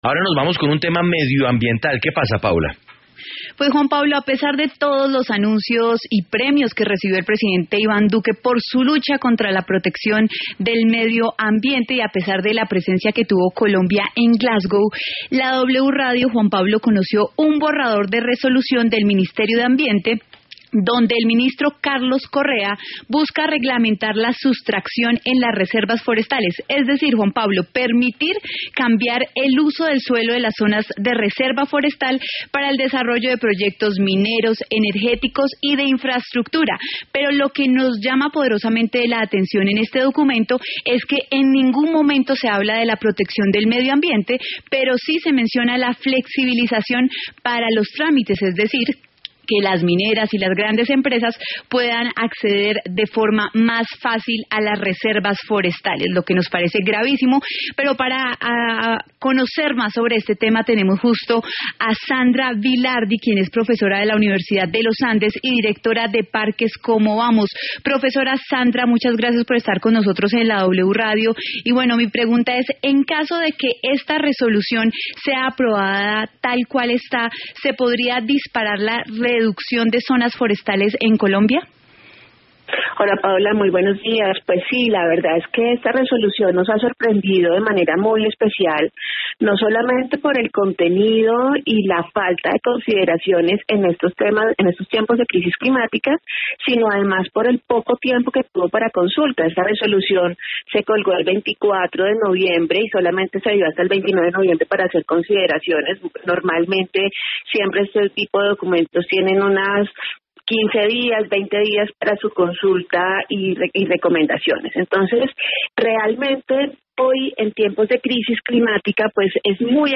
En diálogo con la W Radio